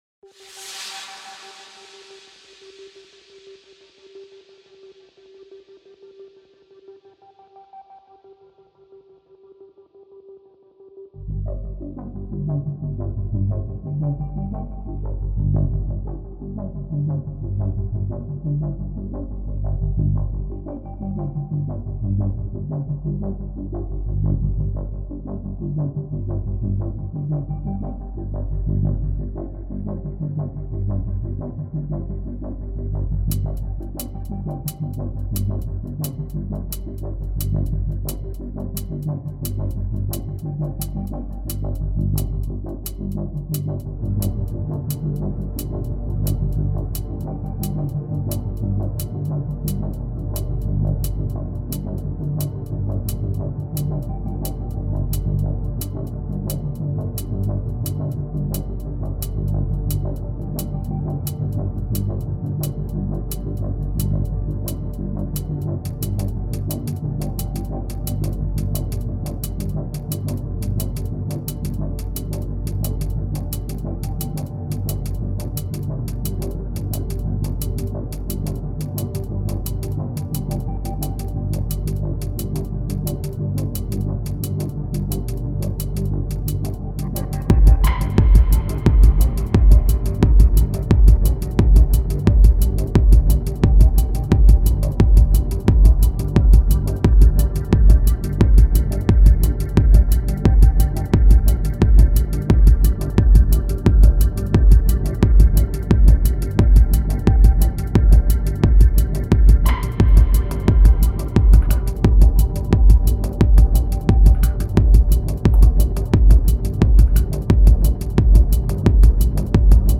Electro | Electronica | Techno